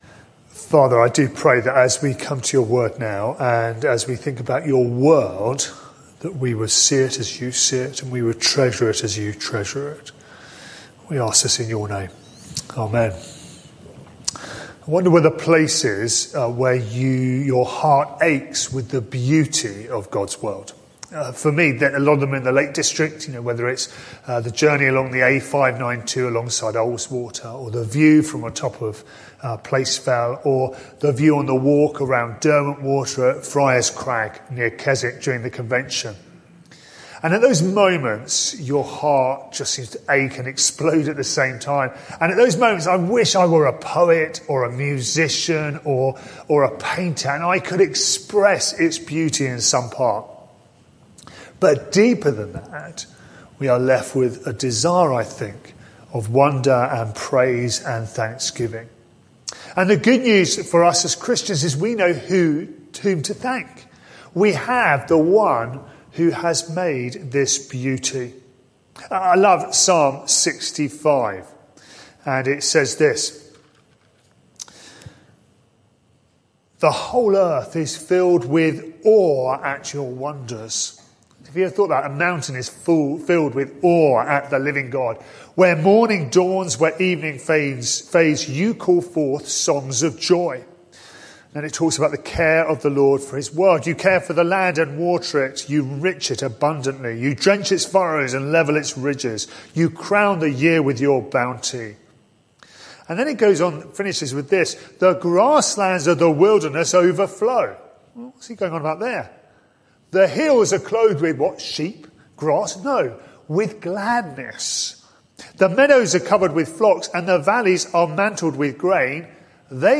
This sermon is part of a series: 16 January 2022